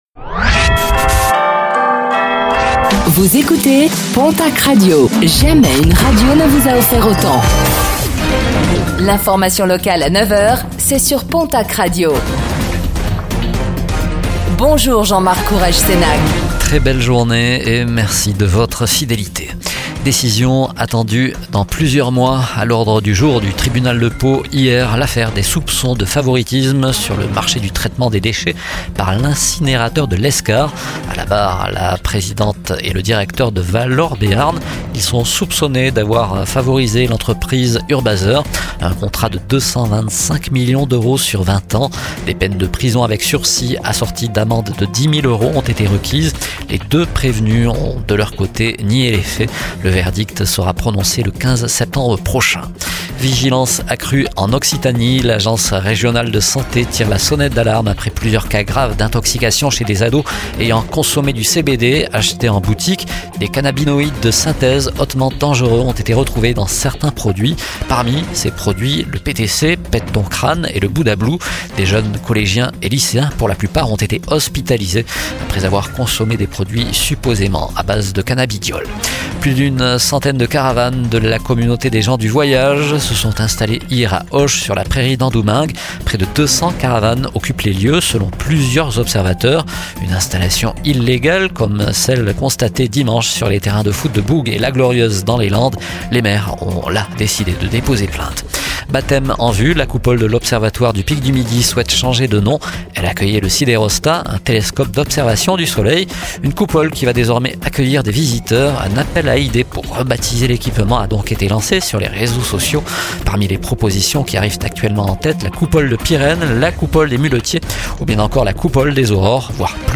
Réécoutez le flash d'information locale de ce mardi 03 juin 2025